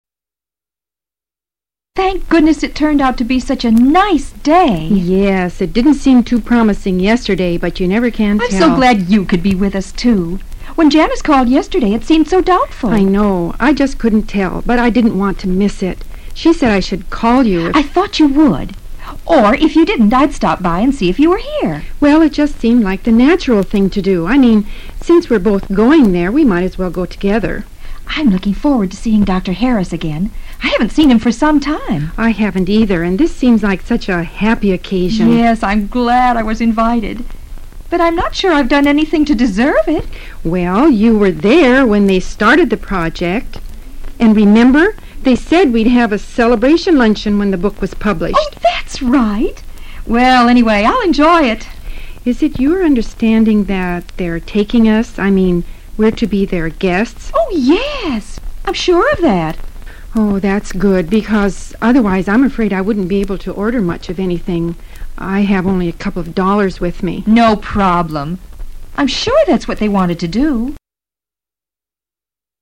Conversaciones en un Autobús
In this first step, you will listen through this conversation between two female colleagues onboard the bus.
conversation03.mp3